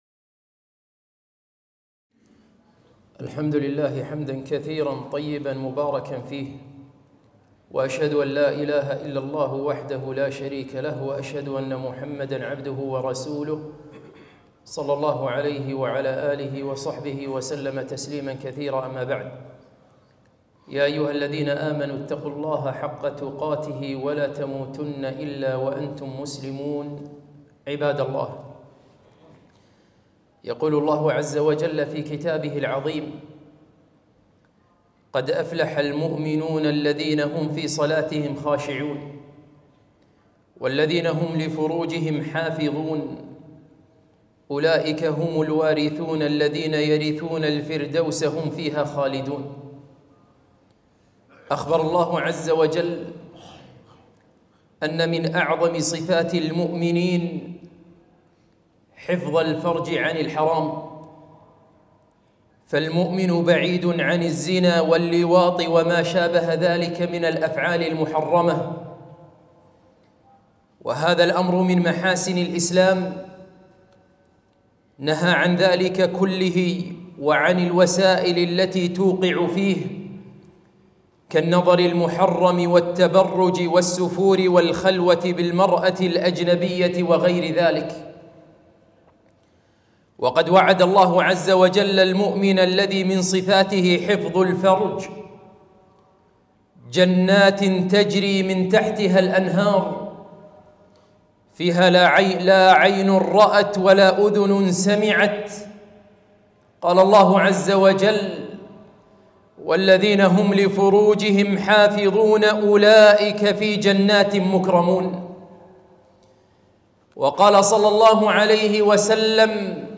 خطبة - حفظ الفروج